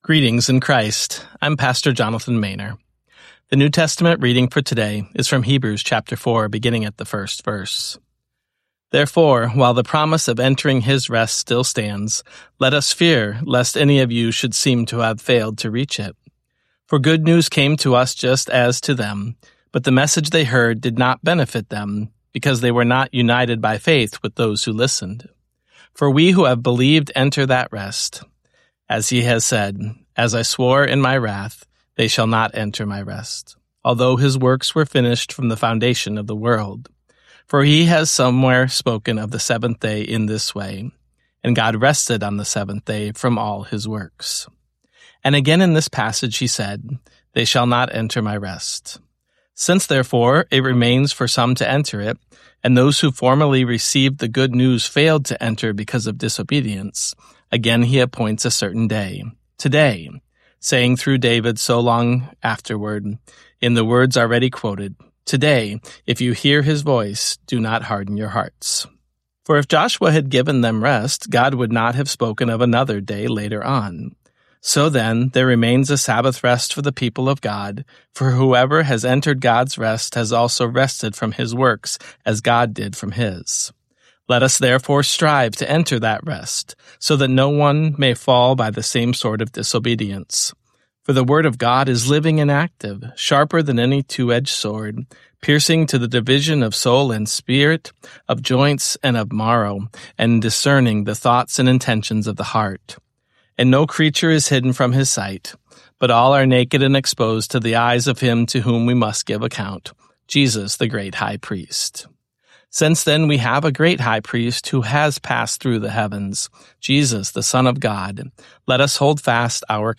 Morning Prayer Sermonette: Hebrews 4:1-16
Hear a guest pastor give a short sermonette based on the day’s Daily Lectionary New Testament text during Morning and Evening Prayer.